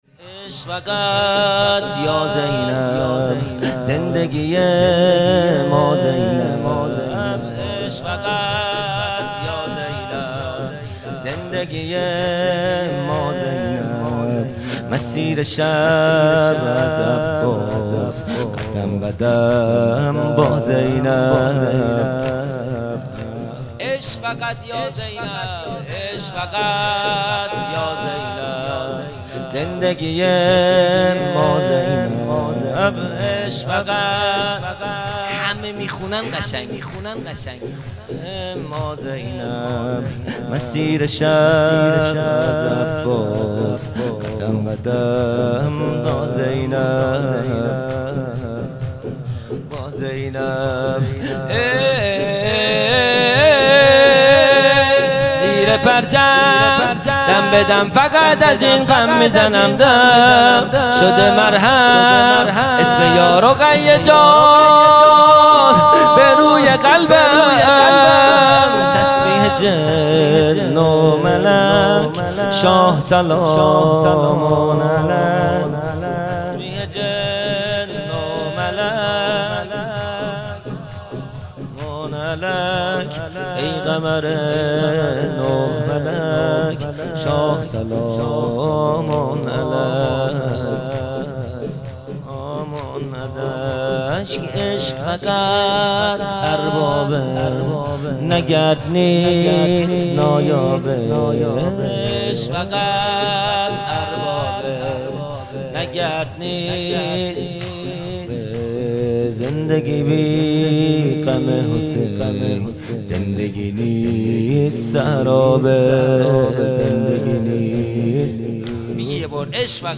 خیمه گاه - هیئت بچه های فاطمه (س) - شور | عشق فقط یا زینب | 14 بهمن 1400
جلسۀ هفتگی | لیله الرغائب